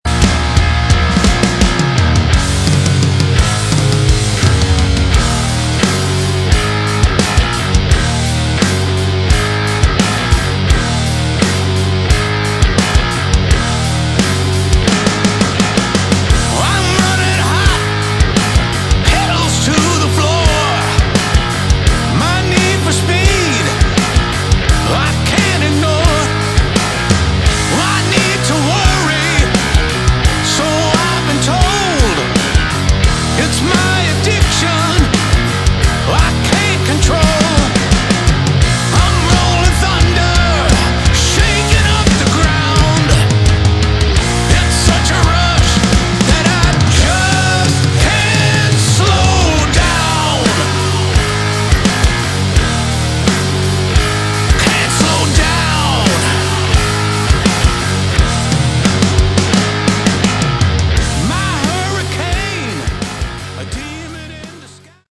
Category: Melodic Metal
vocals, guitar
drums, percussion, vocals
bass, vocals